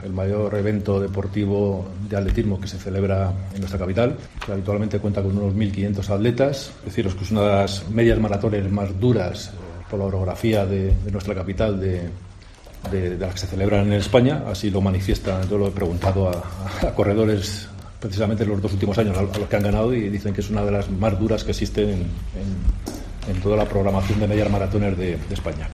Pablo Gómez, concejal de Deportes